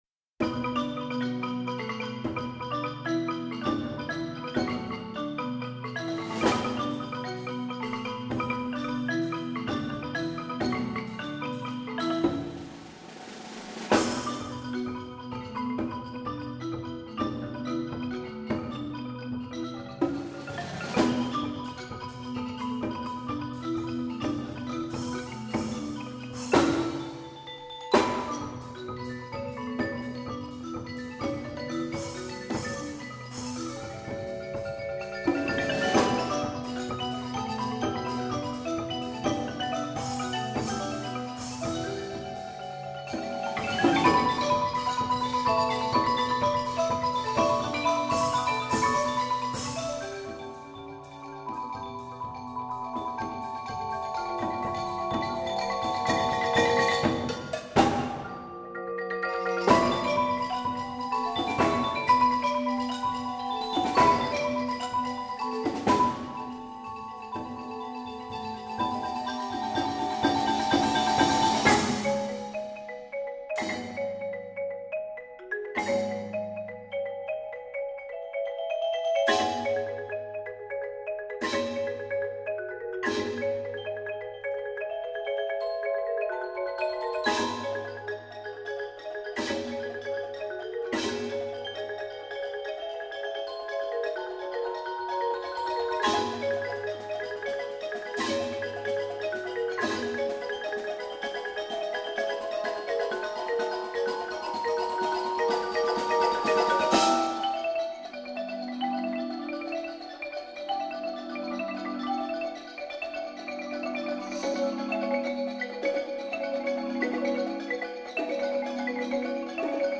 Genre: Percussion Ensemble
# of Players: 12
Xylophone 1
Vibraphone 1, egg shaker
Marimba 1 (4.3-octave)